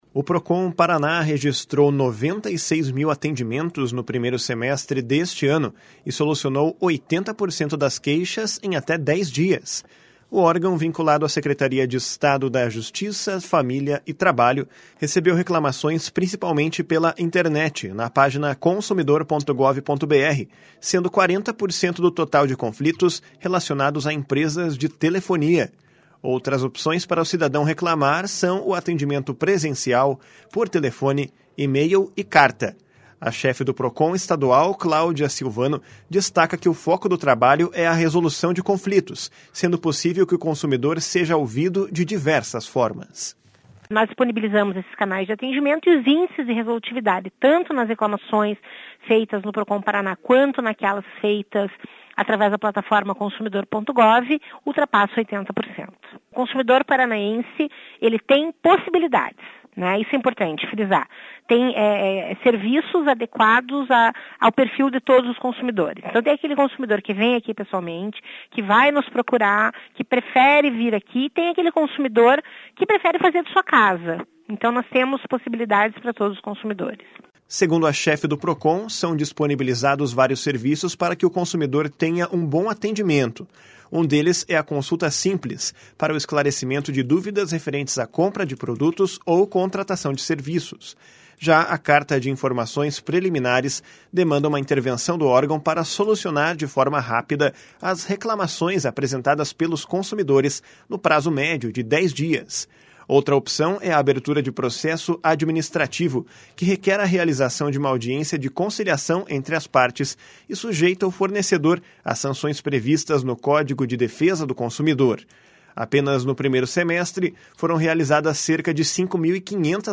A chefe do Procon Estadual, Claudia Silvano, destaca que o foco do trabalho é a resolução de conflitos, sendo possível que o consumidor seja ouvido de diversas formas.
O secretário da Justiça, Ney Leprevost, destaca que o Procon está especialmente atento aos casos envolvendo companhias campeãs no número de reclamações.